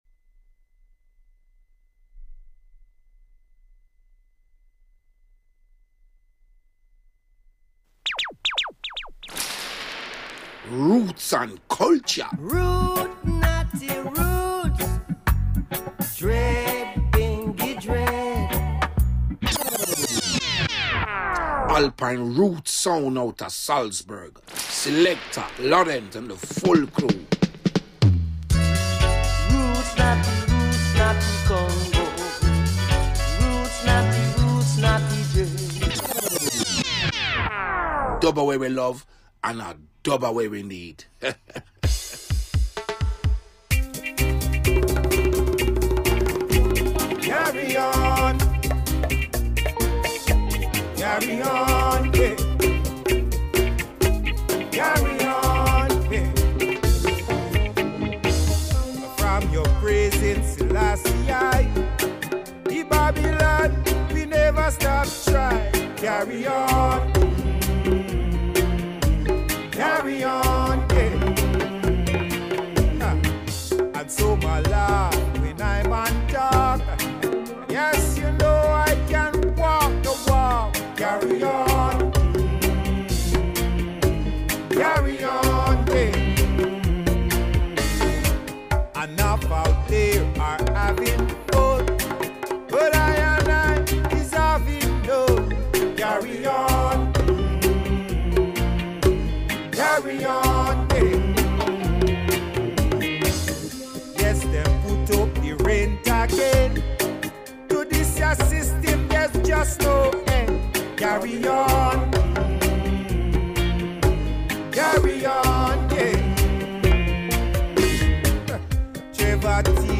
Women in Reggae Special Live Radioshow